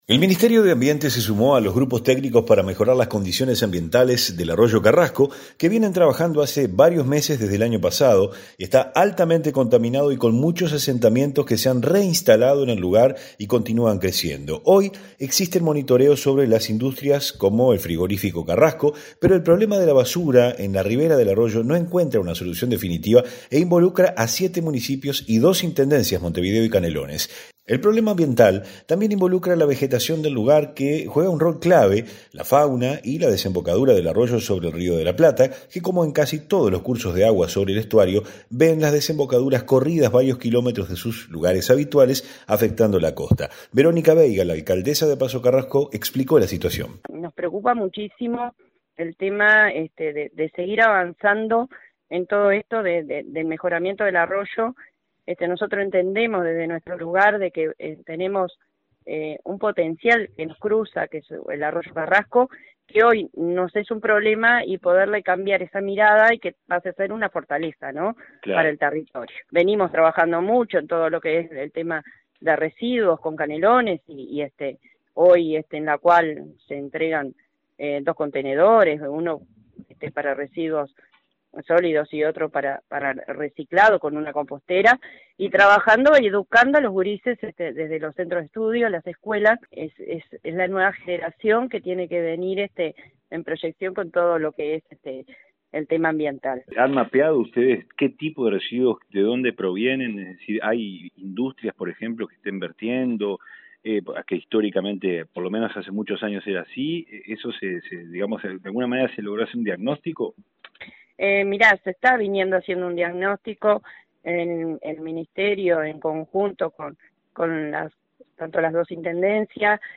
Verónica Veiga la alcaldesa de Pasco Carrasco, explicó la situación.
REPORTE-PASO-CARRASCO.mp3